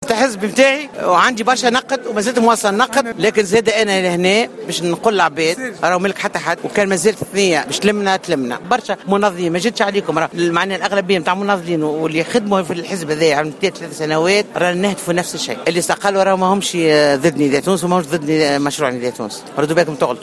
قالت عضو حركة نداء تونس بمجلس نواب الشعب بشرى بلحاج حميدة في تصريح للجوهرة أف أم خلال حضورها اليوم السبت 09 جانفي 2016 في المؤتمر الأول للحزب بسوسة إنها اختارت البقاء في الحزب والنضال من داخله مضيفة ّأن من استقالوا ليسوا ضد نداء تونس أو مشروع النداء.